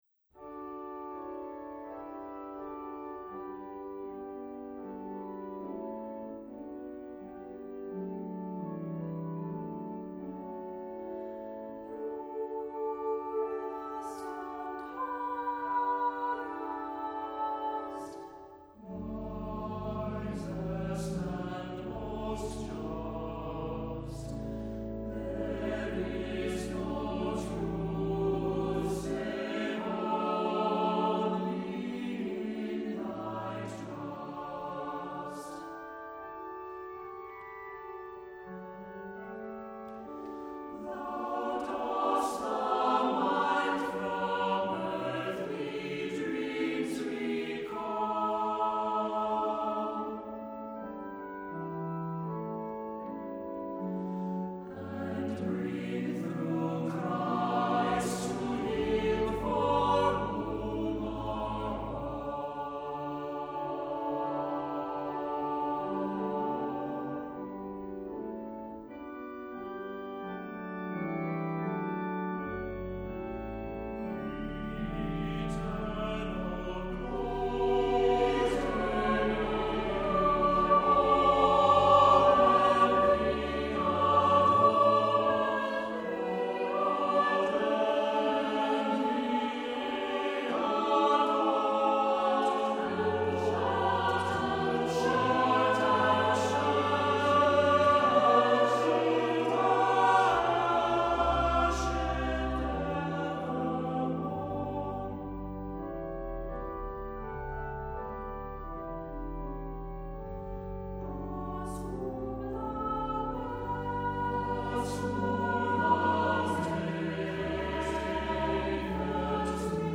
Accompaniment:      With Organ
Music Category:      Christian